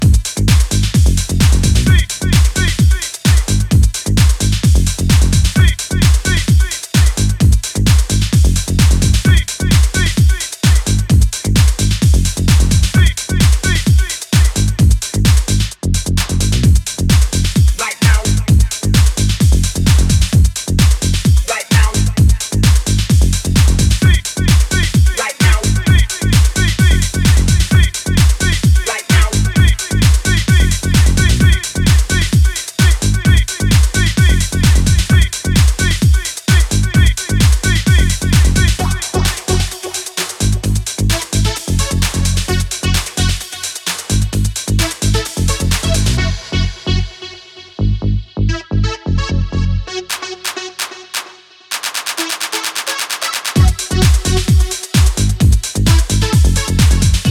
a housier tone with a catchy melody and punchy vocal.